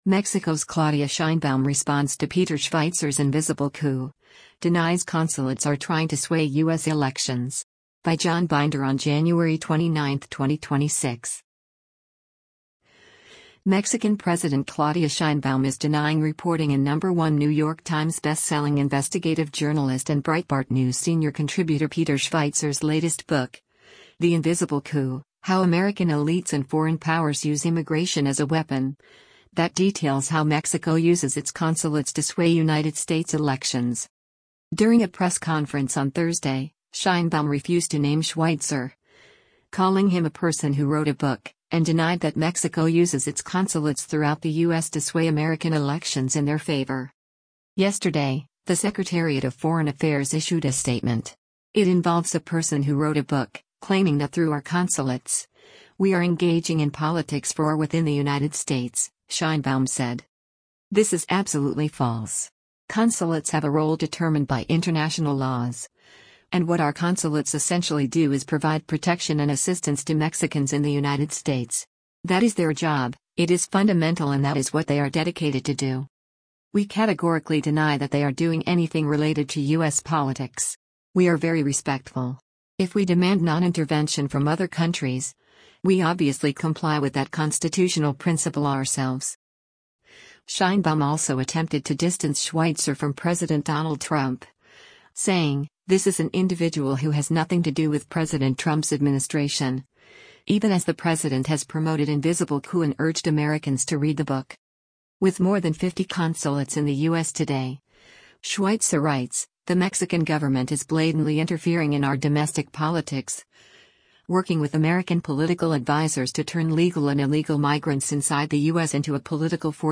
During a press conference on Thursday, Sheinbaum refused to name Schweizer, calling him “a person who wrote a book,” and denied that Mexico uses its consulates throughout the U.S. to sway American elections in their favor.